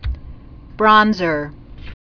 (brŏnzər)